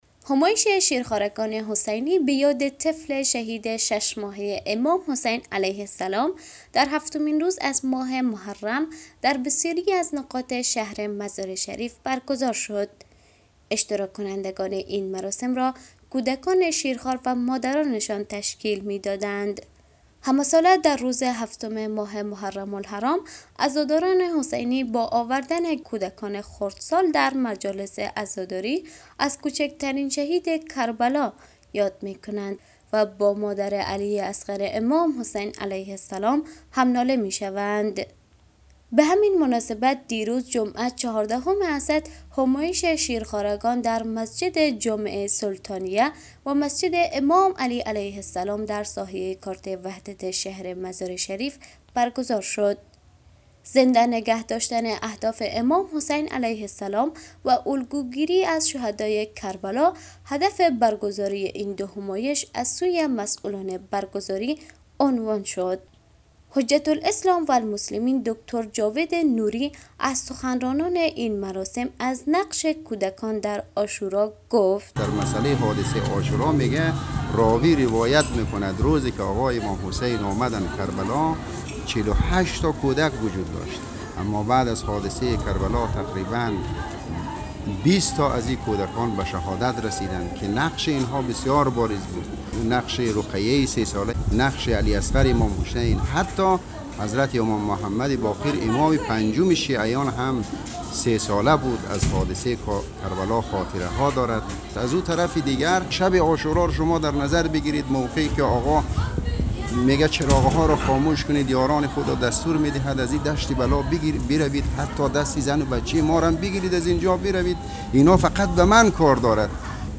همزمان با هفتمین روز ماه محرم آیین باشکوه بزرگداشت شهید شش ماهه کربلا حضرت علی اصغر(ع) با حضور مادران و کودکان شهر مزارشریف برگزار شد.